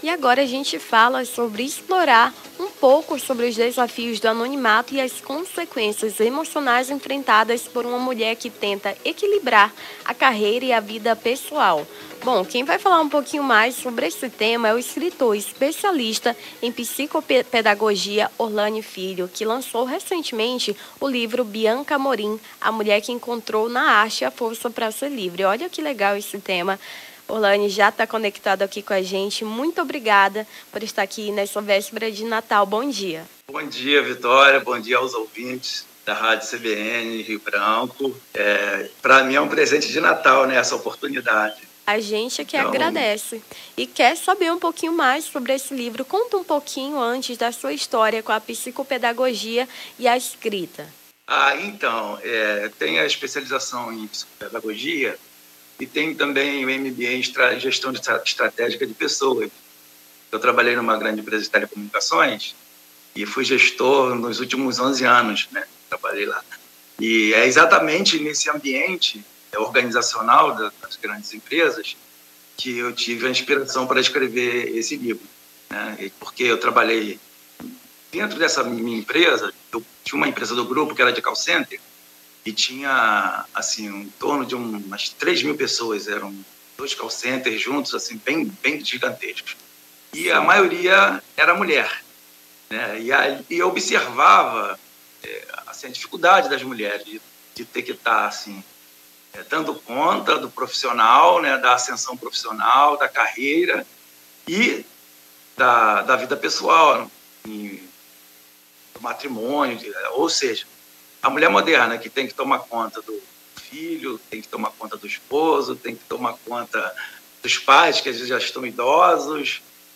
Nome do Artista - CENSURA - ENTREVISTA EQUILÍBRIO CARREIRA E VIDA PESSOAL (24-12-24).mp3